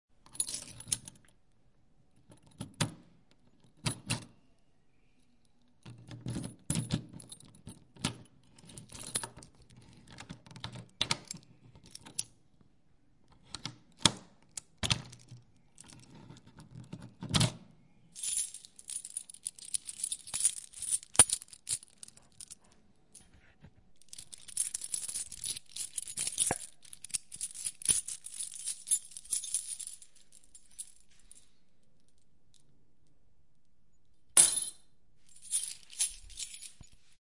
钥匙叮当响有塑料钥匙架
描述：一套金属钥匙与塑料钥匙扣摇晃和摆动。钥匙扣上的各种不同尺寸的钥匙。
装备：在带有EV 635A麦克风的演播室环境中使用SONY PCMM10录制。